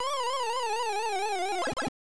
Index of /java/Examples/MrsPacMan/soundfx
died.ogg